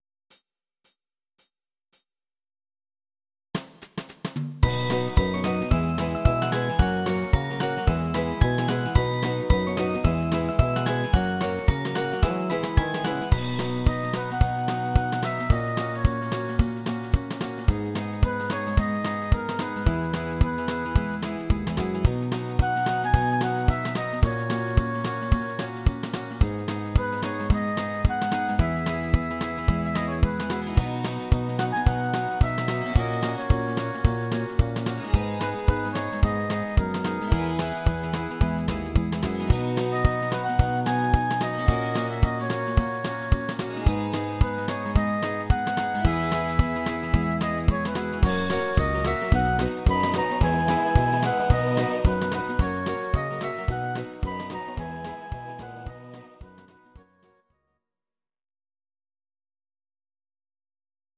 Audio Recordings based on Midi-files
Pop, Rock, 2010s